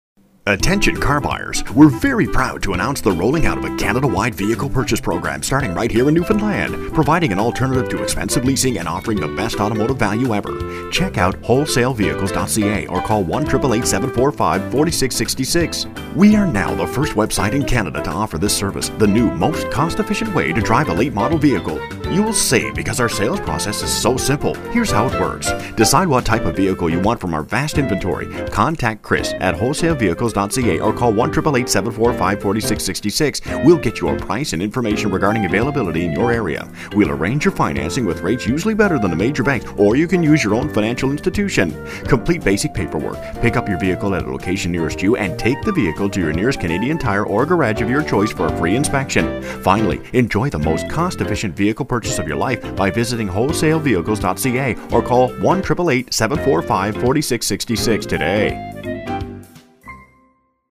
60 second animated soft sell